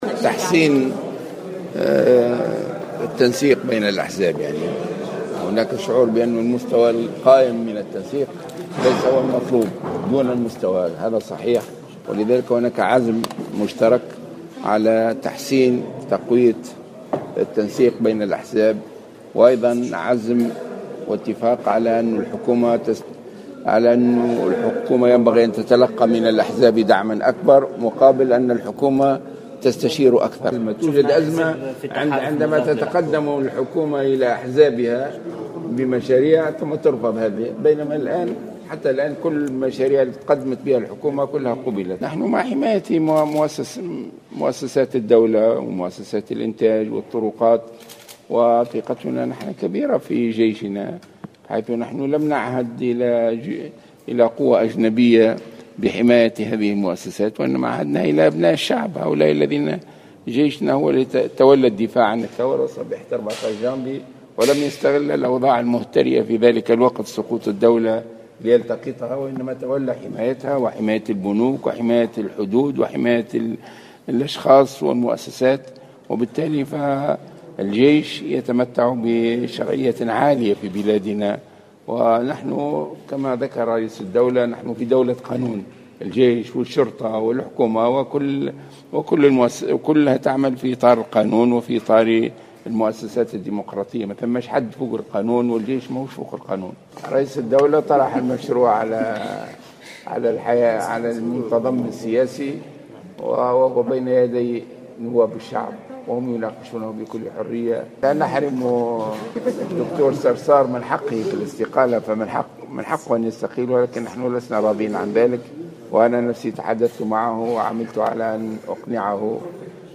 من جانب آخر، شدد الغنوشي، في تصريح لمراسل الجوهرة أف أم، على ضرورة حماية مؤسسات الدولة ومنابع الإنتاج، منوها بقرار رئيس الجمهورية الباجي قائد السبسي القاضي بتكليف الجيش الوطني بحماية هذه المؤسسات.